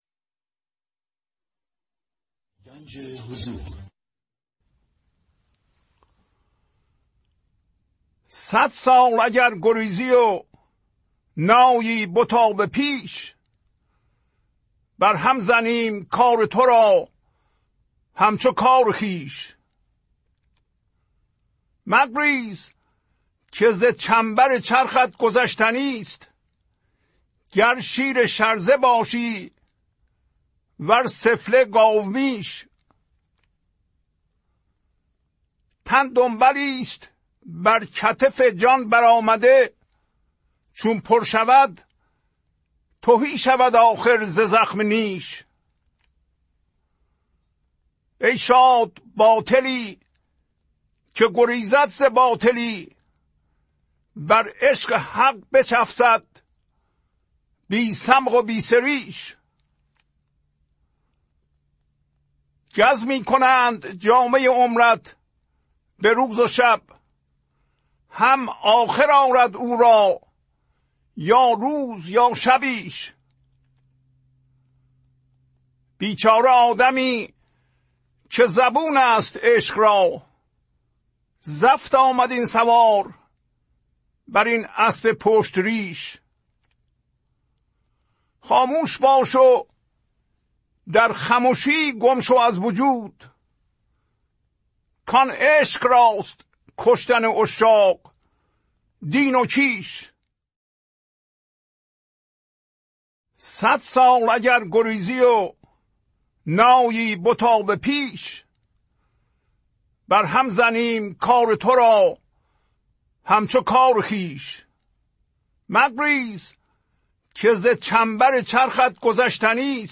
خوانش تمام ابیات این برنامه - فایل صوتی
1005-Poems-Voice.mp3